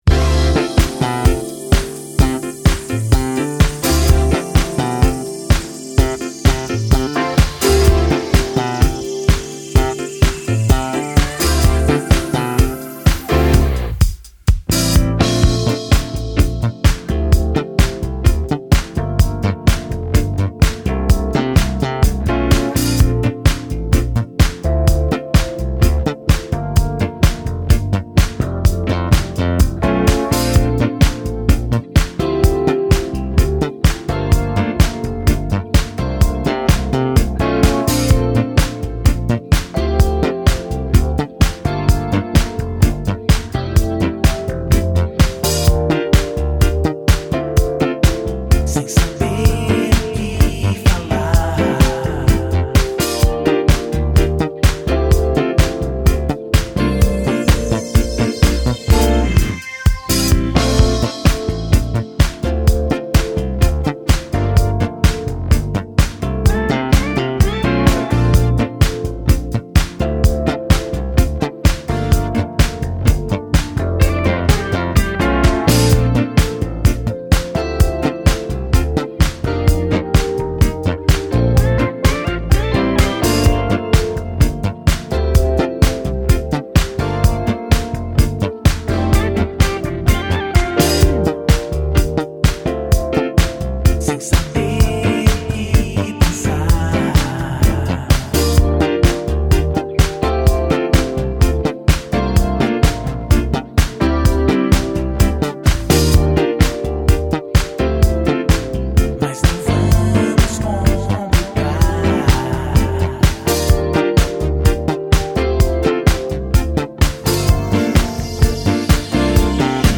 (Dub)